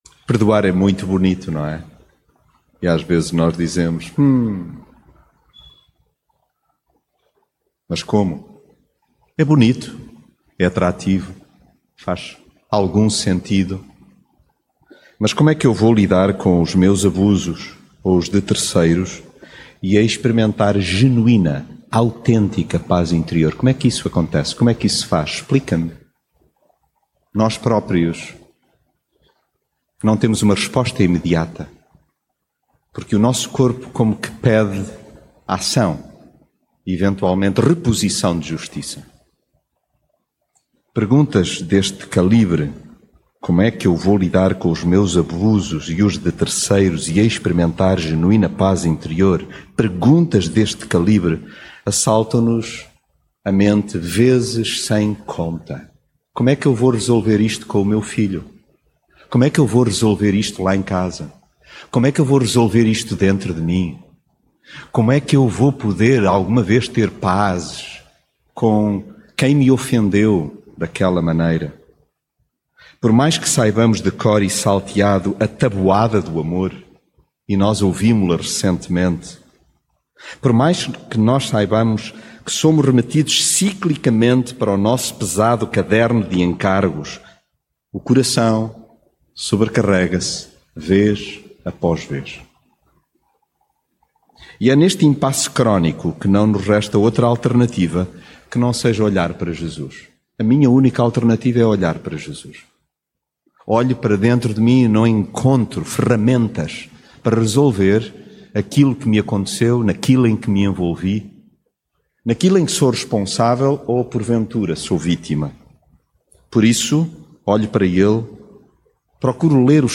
70 x 7 mensagem bíblica Ah, perdoar é muito bonito, mas como lidar com os nossos abusos e os de terceiros e experimentar genuína paz...